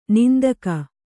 ♪ nindaka